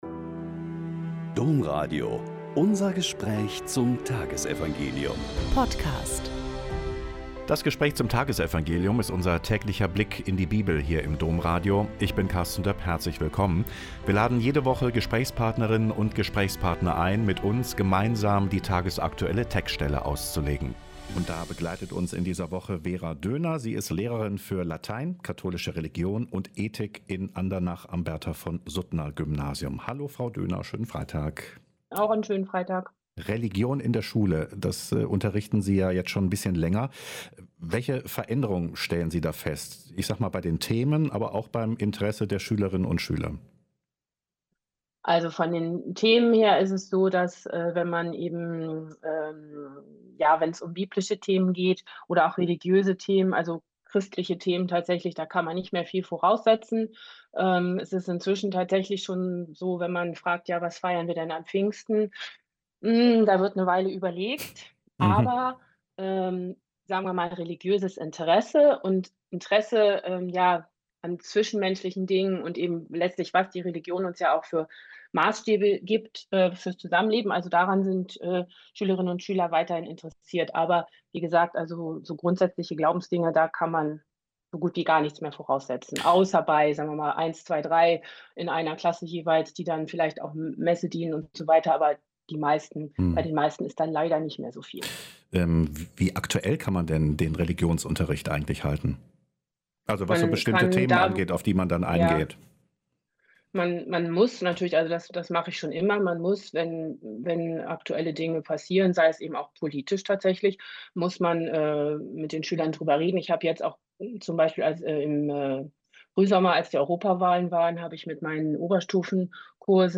Mk 2,1-12 - Gespräch